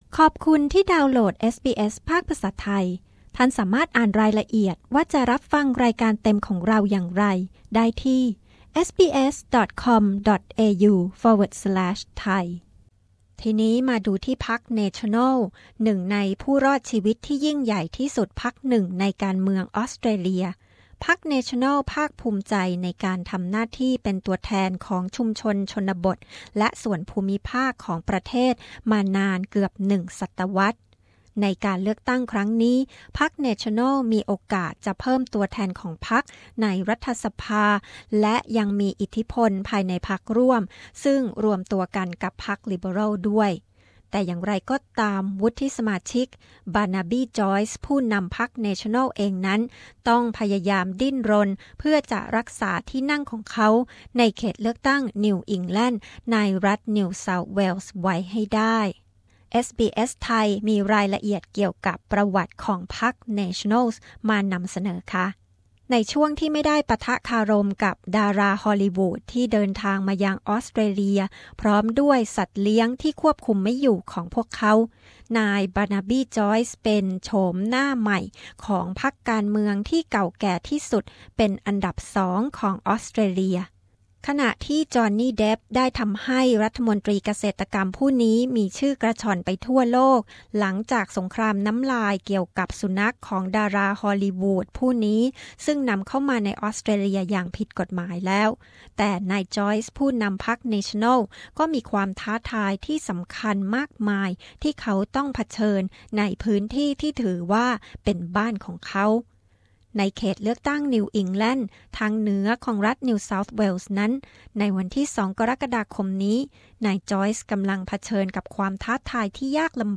ติดตามประวัติและสถานการณ์ของพรรคเนชั่นแนลส์ ซึ่งเป็นพรรคที่เป็นพันธมิตรกับพรรคลิเบอรัล สำหรับการเลือกตั้ง สหพันธรัฐ 2 กรกฎาคมนี้ เอสบีเอส มีรายงานพิเศษ